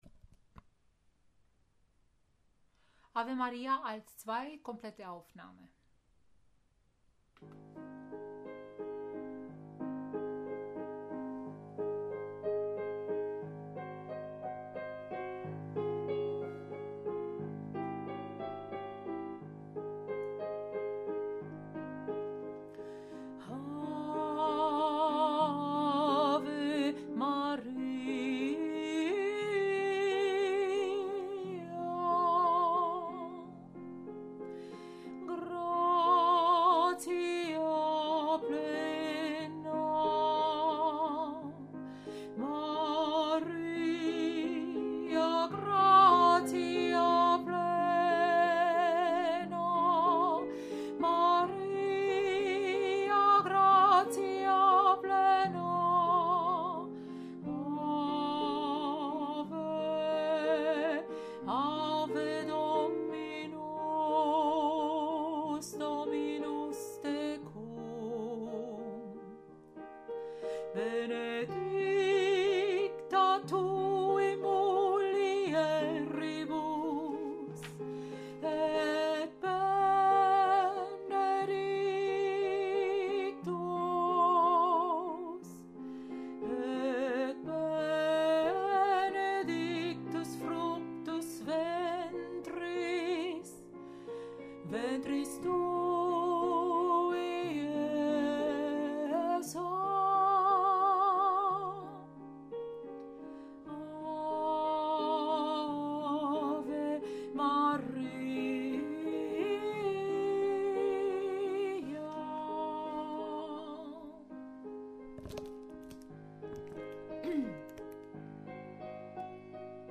Ave Maria – Alto 2-komplett
Ave-Maria-A2-Komplett.mp3